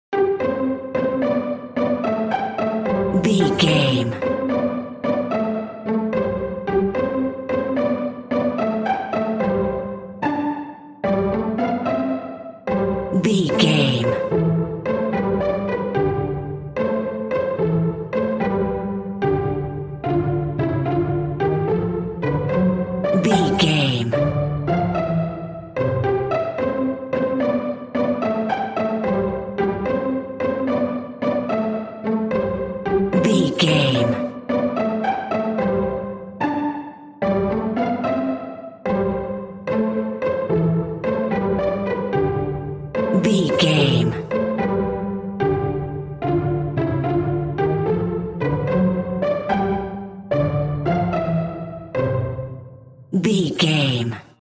Ionian/Major
nursery rhymes
childrens music
Celeste